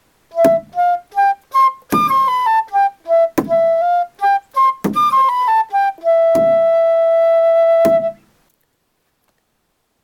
Chant Melody audio (no words)
idunna_chant.mp3